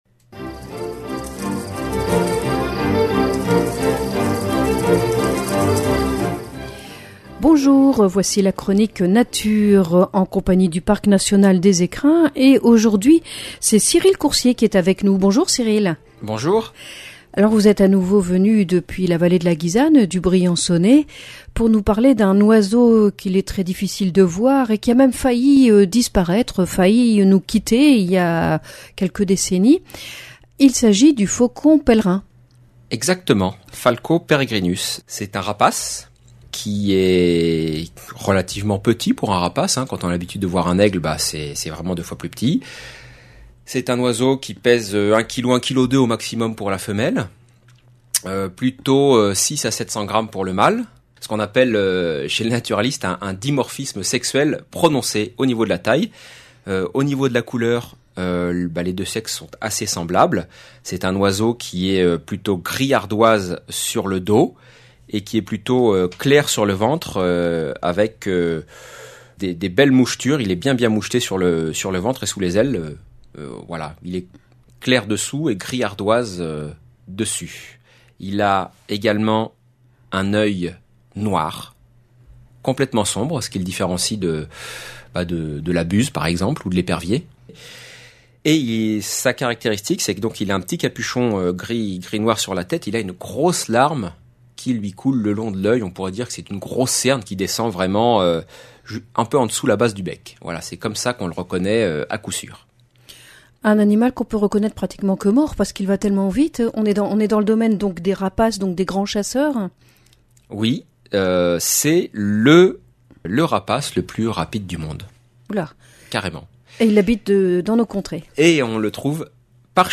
Chronique nature Ce petit rapace vit dans les milieux rocheux.